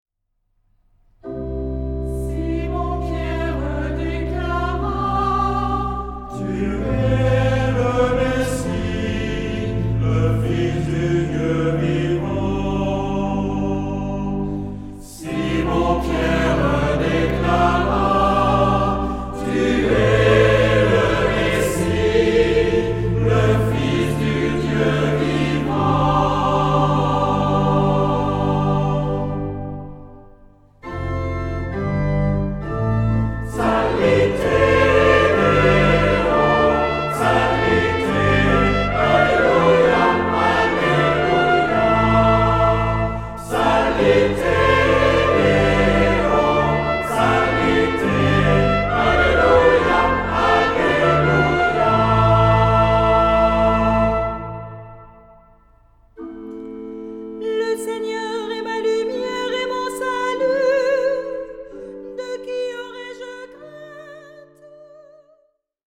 Género/Estilo/Forma: Sagrado ; tropario ; Salmo
Carácter de la pieza : con recogimiento
Tipo de formación coral: SATB  (4 voces Coro mixto )
Instrumentos: Organo (1) ; Instrumento melódico (1)
Tonalidad : do mayor ; la menor